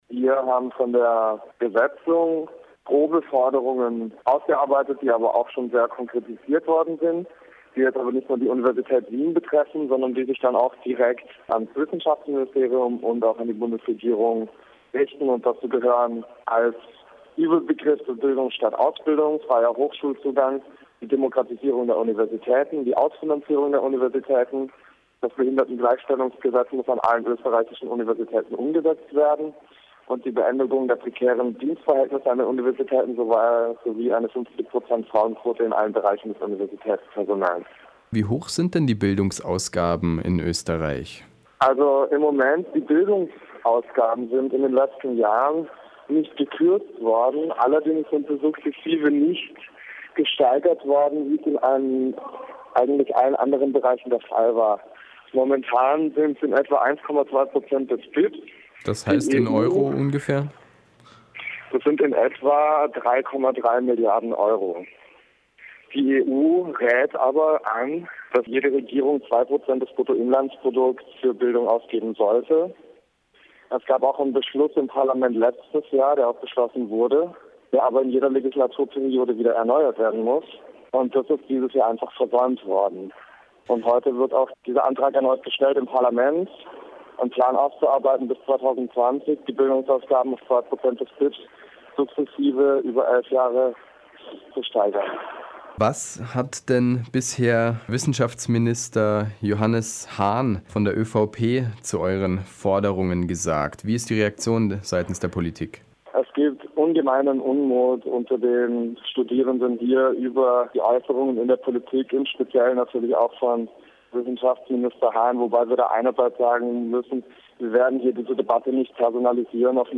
Gespräch über konkrete Forderungen der Studierenden und die abweisenden Reaktionen politischer Entscheidungsträger.